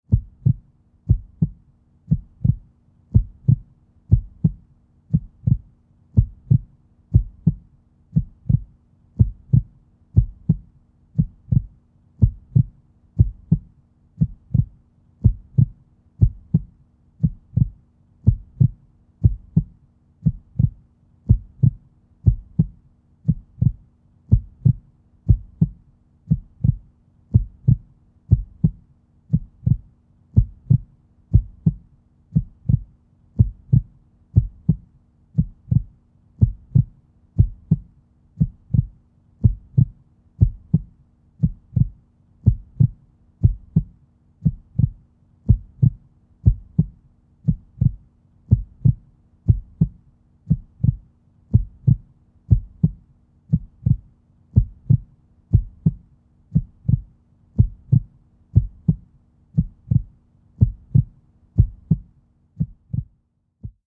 LCvsAOqSMZg_bruitcardiaque.wav